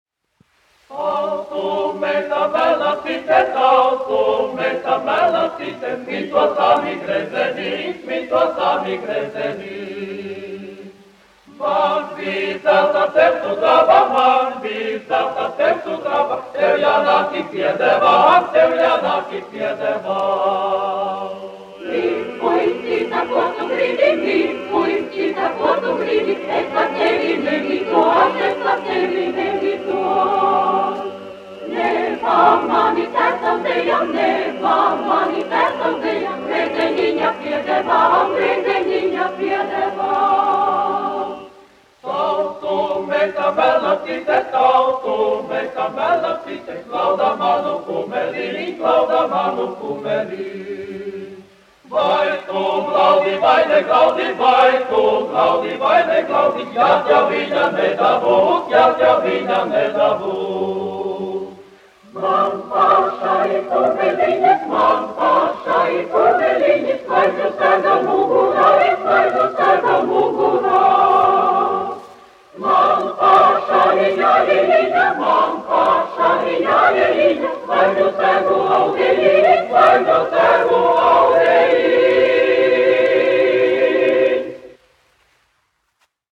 Andrejs Jurjāns, 1856-1922, aranžētājs
Latvijas Radio koris, izpildītājs
Kalniņš, Teodors, 1890-1962, diriģents
1 skpl. : analogs, 78 apgr/min, mono ; 25 cm
Latviešu tautasdziesmas
Kori (jauktie)
Skaņuplate